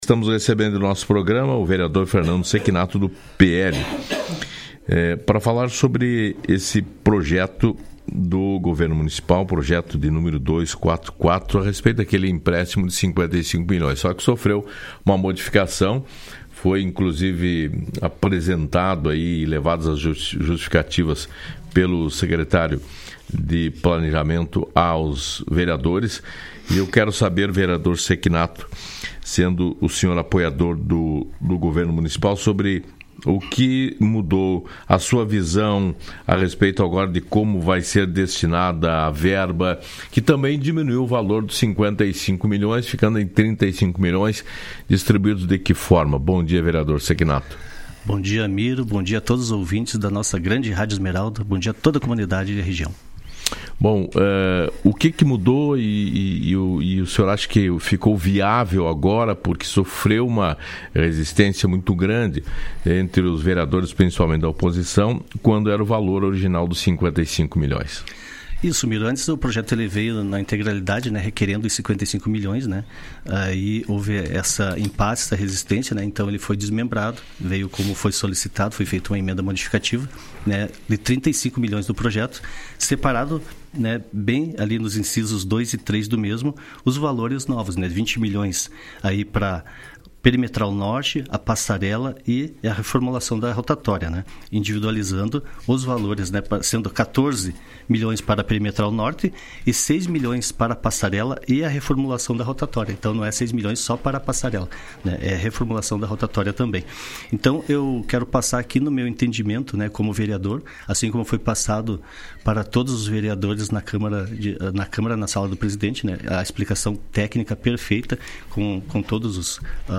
ENTREVISTA-CECHINATO-18-12.mp3